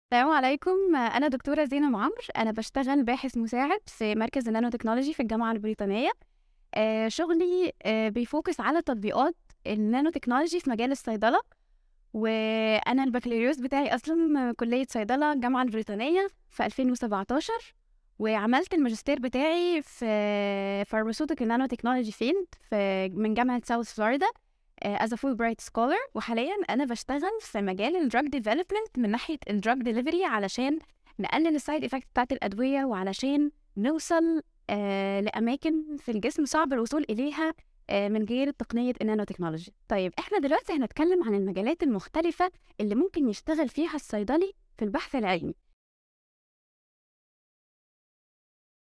زي ما وعدتكم بمقابلات مع الصيادلة في مختلف المجالات ⚕✨